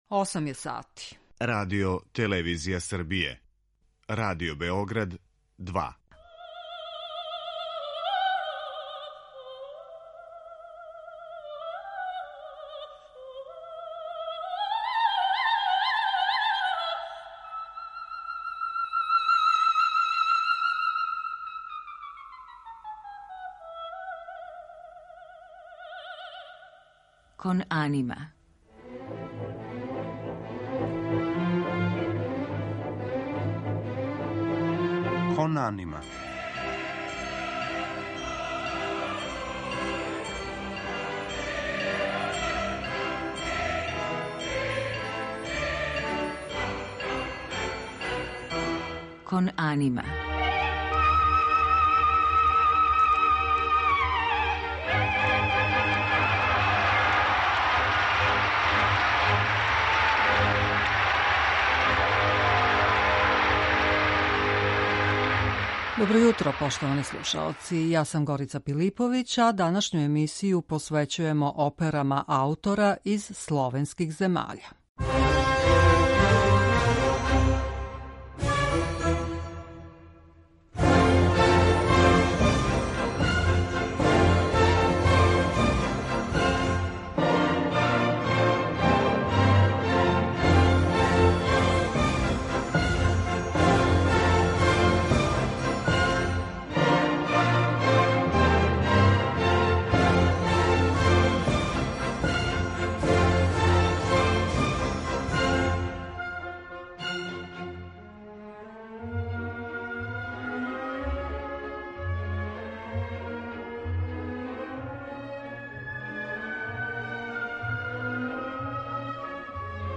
можете слушати одабране одломке опера аутора из словенских земаља.